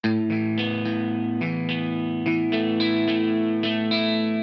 IRON2でアルペジオを使ったギターロックを作る！
５小節目以降に重ねている、もう一本のギターは構成音＆テンションをずらしています。
これにより、楽曲全体で見た時には、AM7(9)→E6(9)という進行になります。
こうしてギターごとに使う度数を変えることで、楽曲全体で深みのある響きとなります。
08_Arpeggio3.mp3